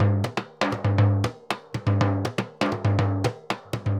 Surdo 2_Candombe 120_1.wav